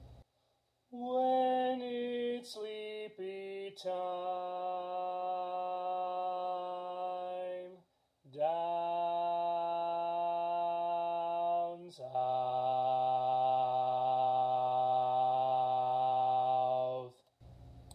Key written in: C Major
Type: Barbershop
Each recording below is single part only.